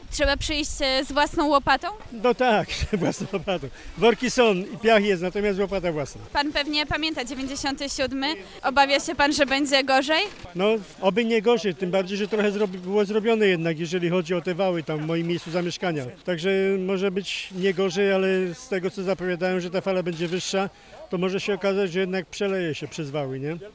Do ZDiUMu przyjechał też mieszkaniec Osobowic.
na-strone_2_mieszkaniec-Osobowic.mp3